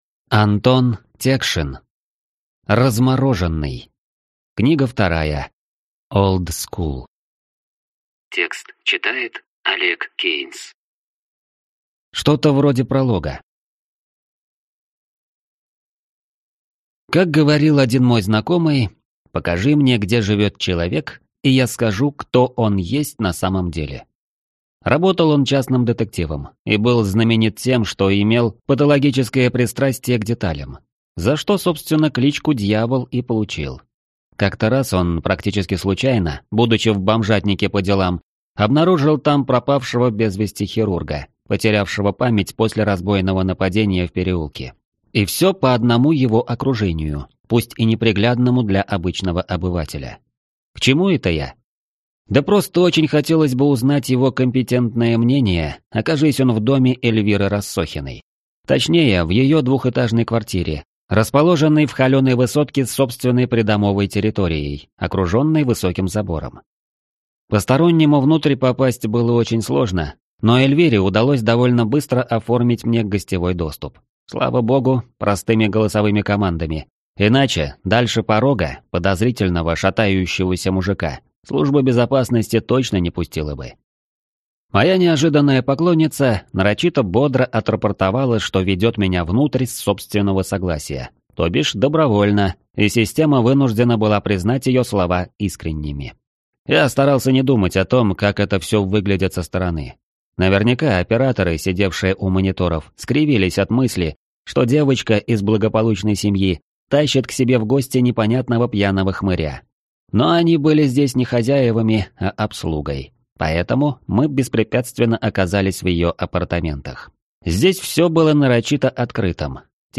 Аудиокнига Размороженный. Книга 2. Oldschool | Библиотека аудиокниг